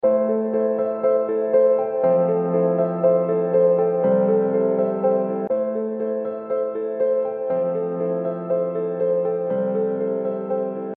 Производим запись, и делаем два трека, сперва идет запись от M50, а после от HD 650. В первом треке громкость для участка HD 650 поднята на 10 дБ.
Визуально видно, что при поднятии а 10 дБ уровень громкости с HD 650 не дотягивает до уровня громкости M50.